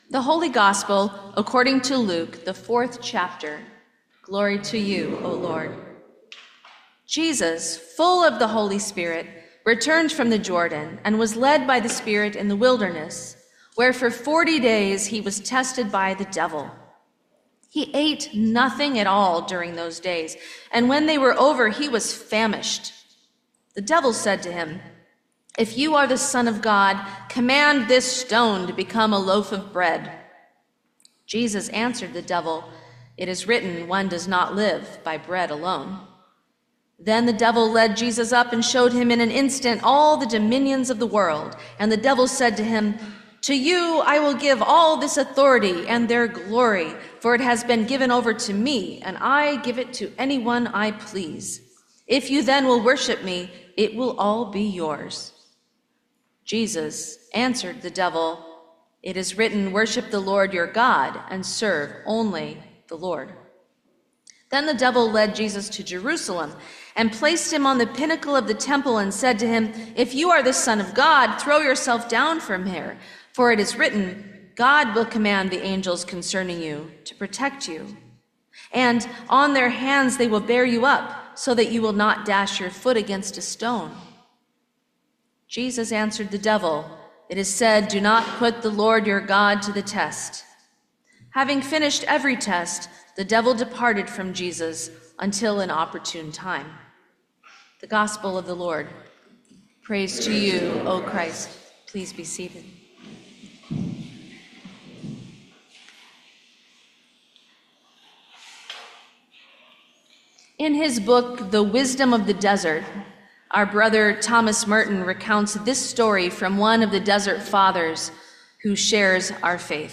Sermon for the First Sunday in Lent 2025